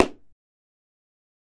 Звуки молотка судьи
На этой странице собраны звуки молотка судьи — от четких одиночных ударов до протяжных стуков.
Легкий и простой